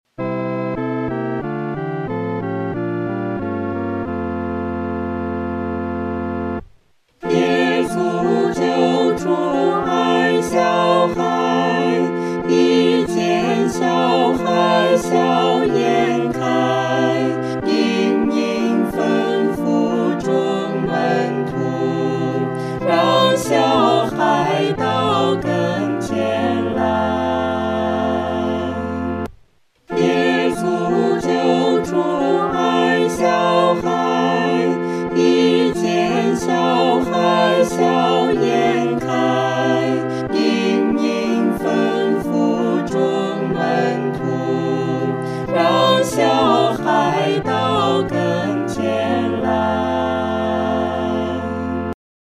合唱
四声
这首诗歌可用较流动的中速来弹唱。